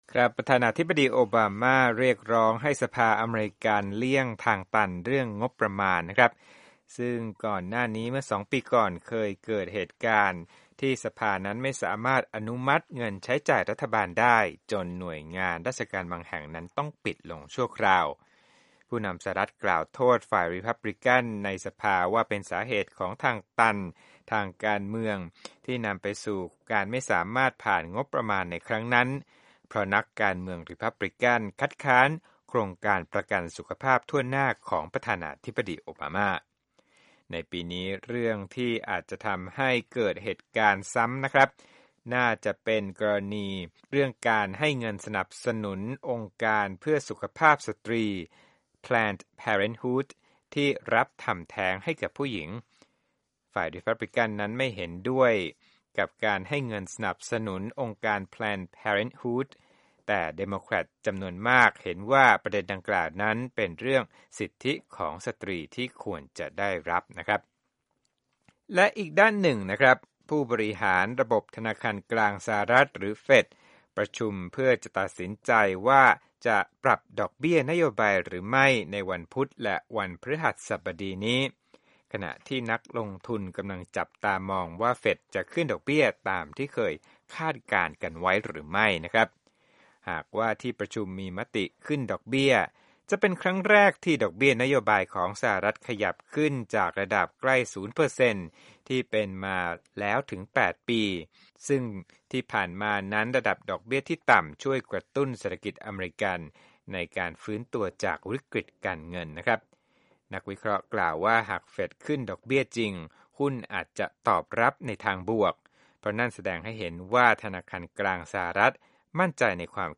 ธุรกิจ